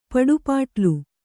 ♪ paḍu pāṭlu